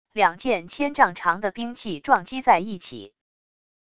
两件千丈长的兵器撞击在一起wav音频